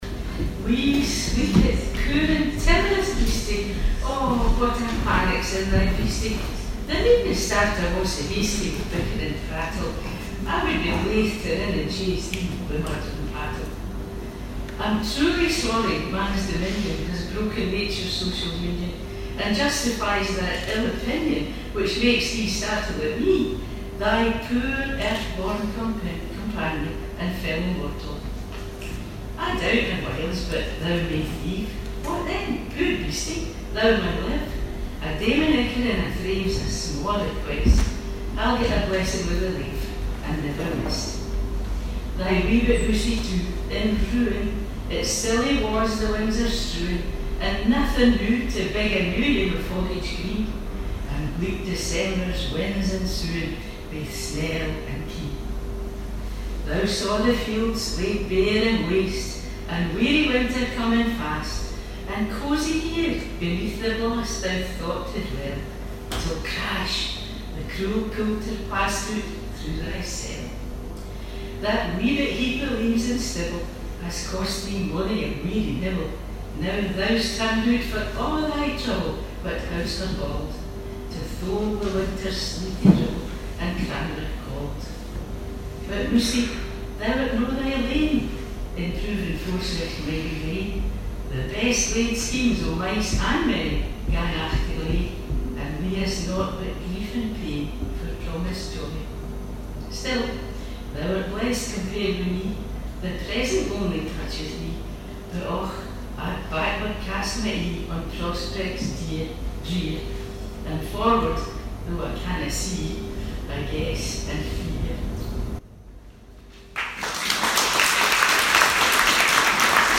Burns Night Supper -  25 January 2019